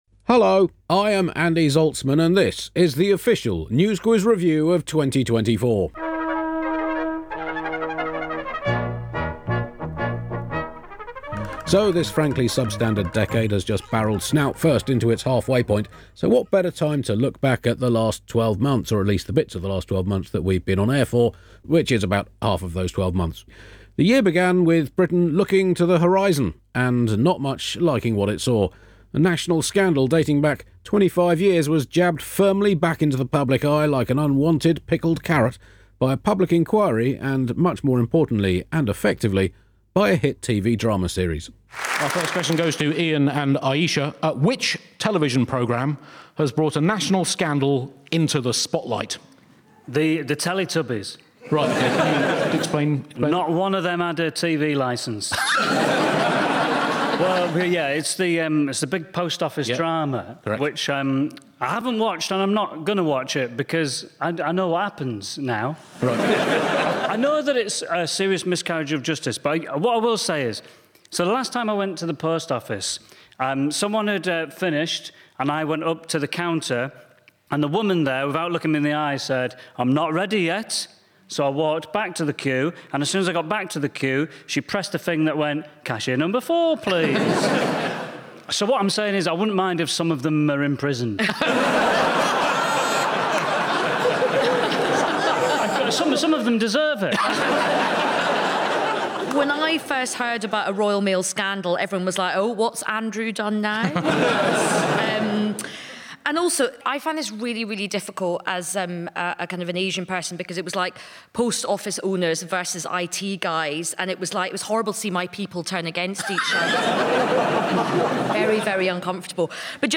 Topical panel quiz show, taking its questions from the week's news stories.